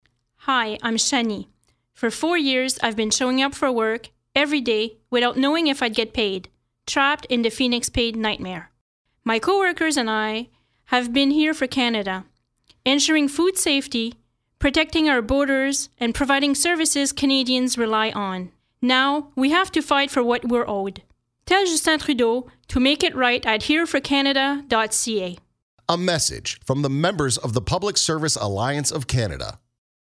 Annonce radio anglophone N.-B.1.15 Mo